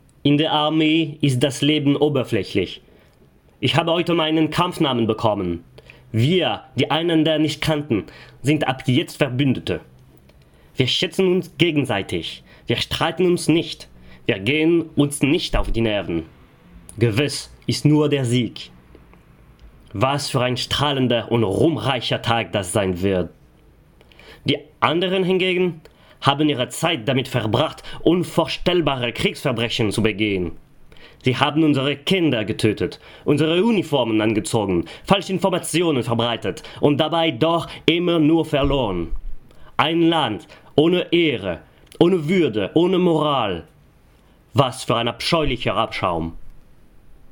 The Few Churchill's speech excerpt (EN)
23 - 40 ans - Baryton